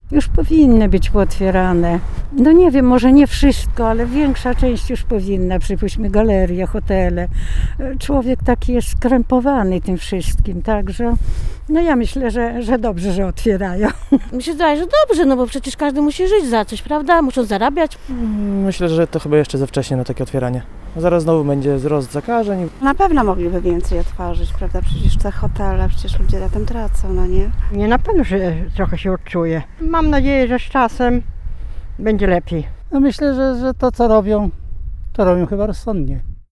A jak te ostatnie decyzje rządzących oceniają ełczanie? Pytała o to reporterka Radia 5.
Sonda-1.mp3